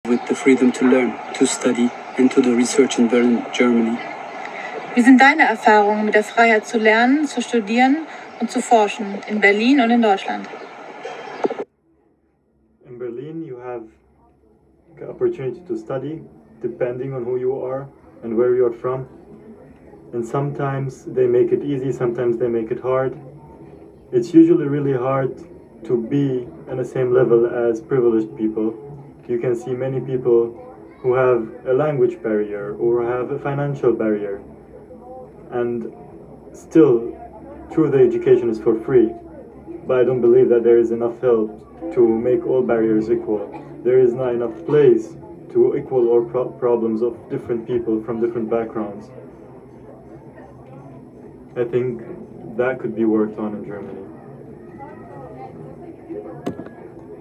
Off University / Lange Nacht der Wissenschaften @ Berlin